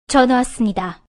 알림음 8_전화왔습니다.ogg